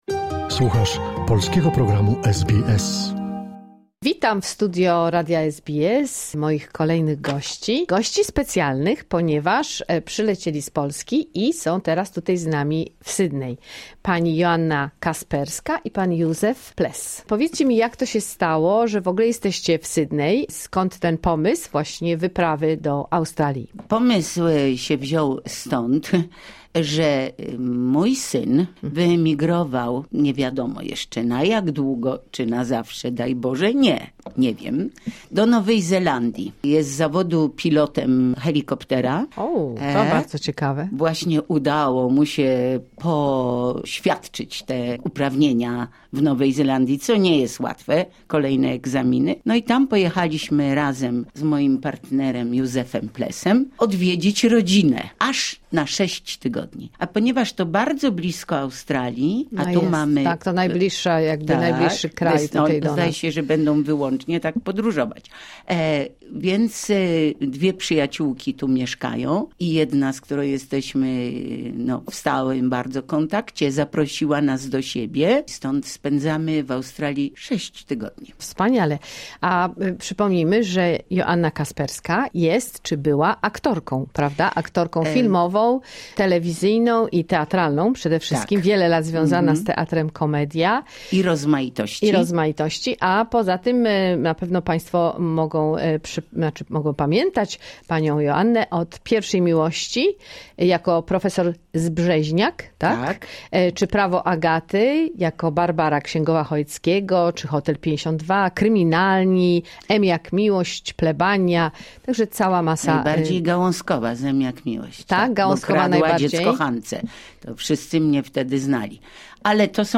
Pierwsza część rozmowy z wyjątkową parą z Polski - aktorką i poetą.